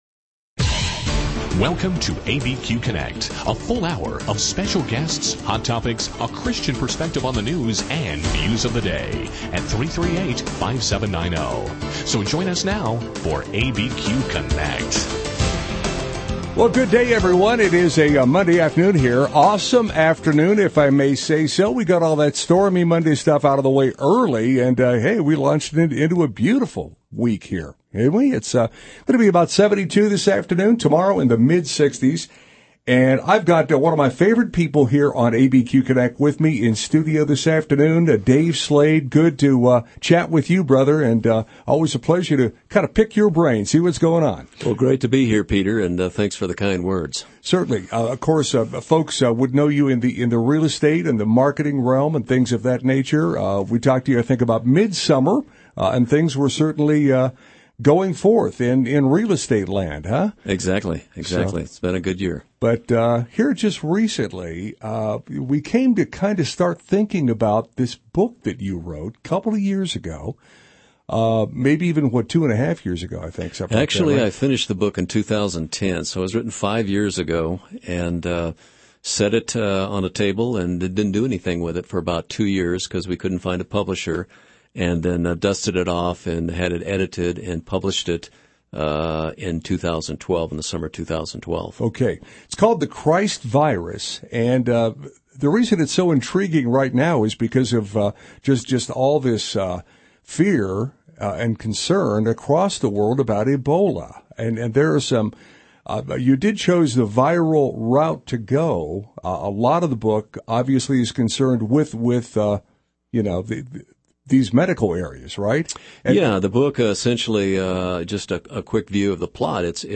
ABQ Connect radio interview 10-27-14